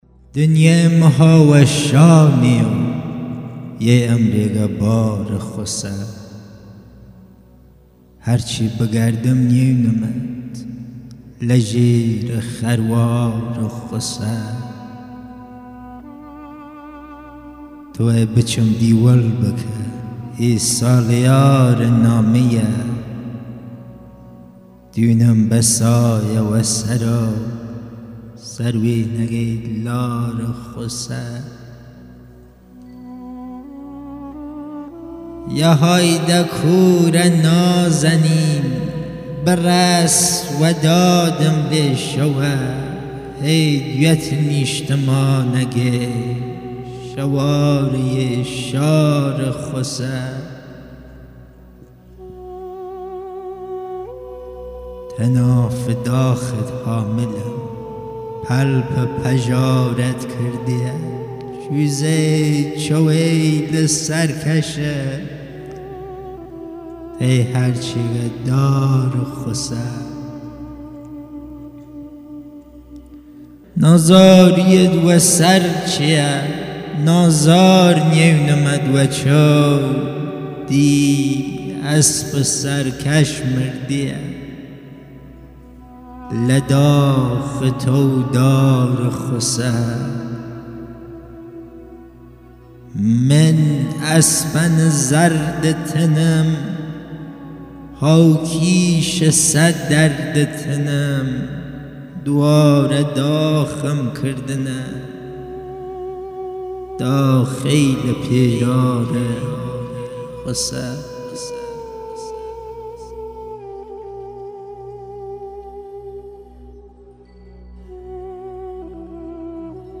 آلبوم صوتی مجموعه شعر کردی کلهری
با نوای زیبا و دلربای اساتید صاحب فن "کمانچه نوازی
پیانو نوازی اساتید زنده یاد بابک بیات و انوشیروان روحانی
قره نی نوازی